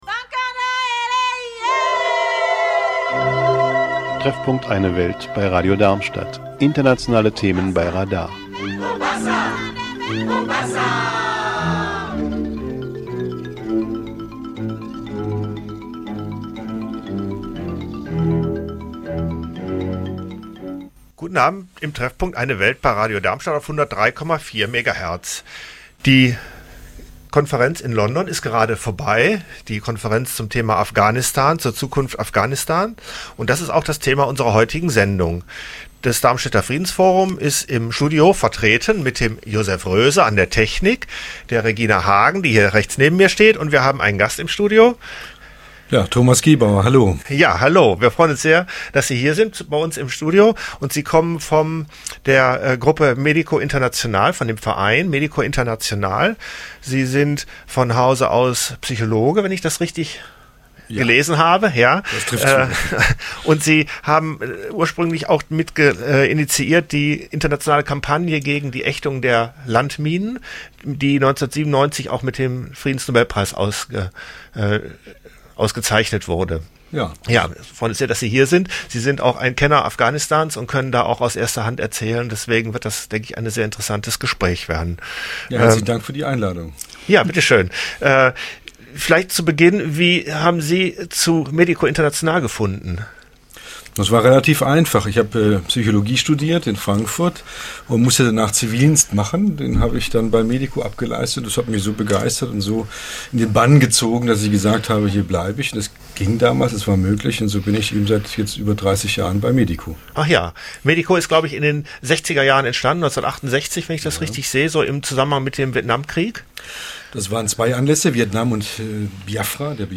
Studiogespräch